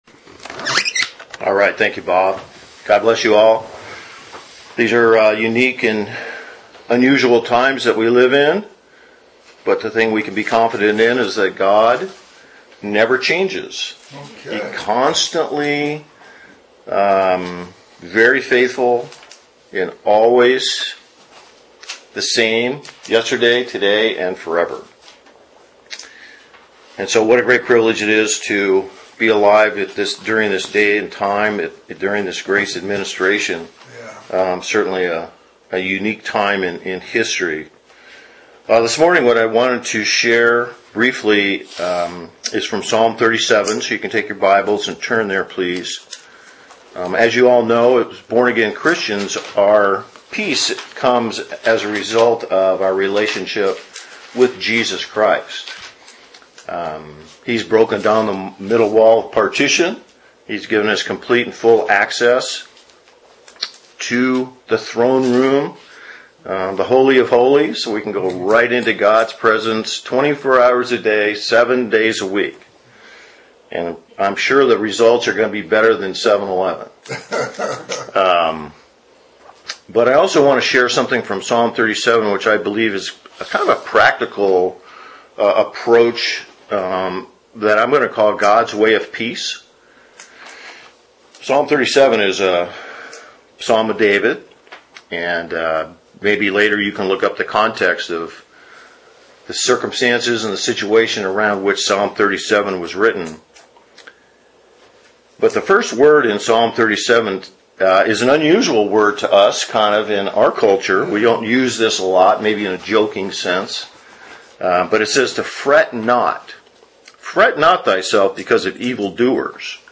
Gods Way of Peace Details Series: Conference Call Fellowship Date: Tuesday, 24 March 2020 Hits: 836 Play the sermon Download Audio ( 3.55 MB )